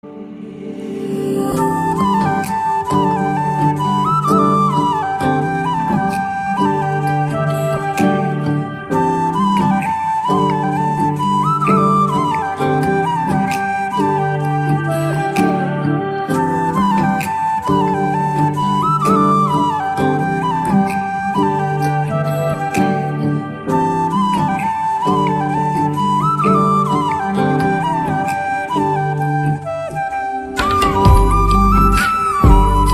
Bansuri Ringtones Flute Ringtones
Instrumental Ringtones
Romantic Ringtones